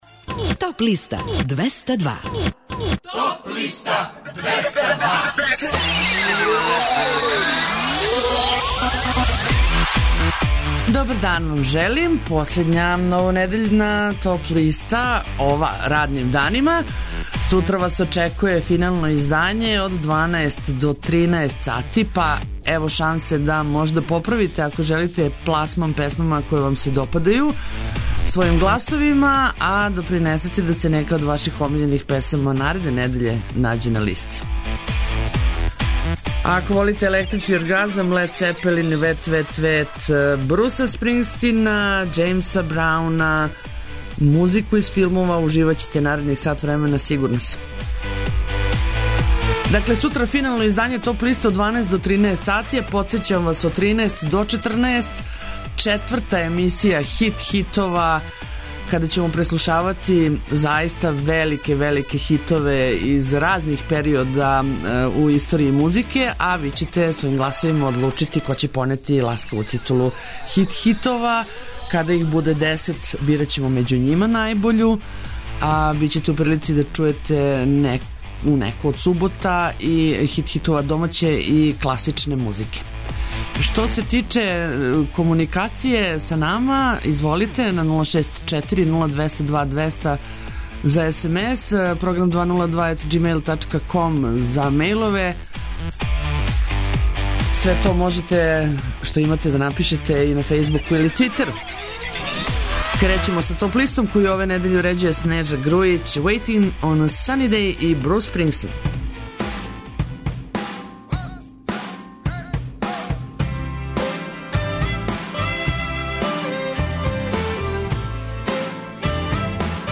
Чујте и композиције које су се нашле на подлисти лектира, класика, етно, филмска музика...